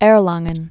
(ĕrlängən)